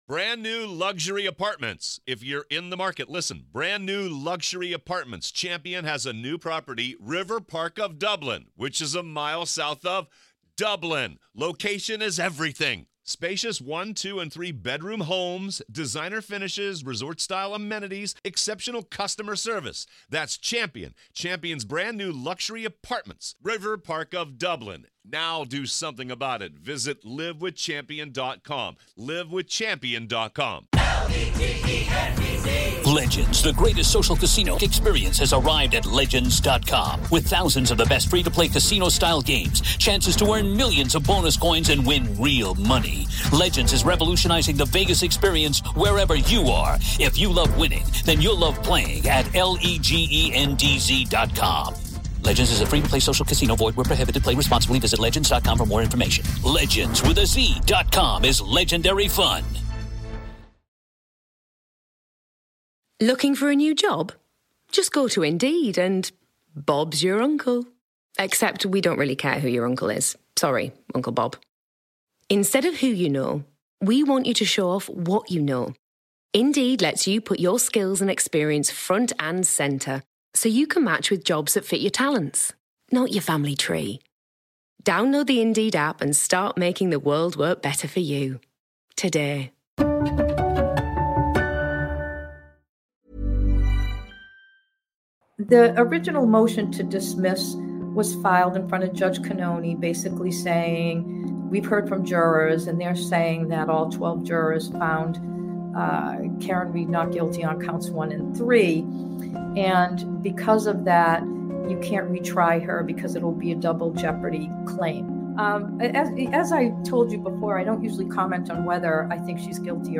Karen Read - Will charges be thrown out? A retired Massachusetts' Judge joins me to discuss.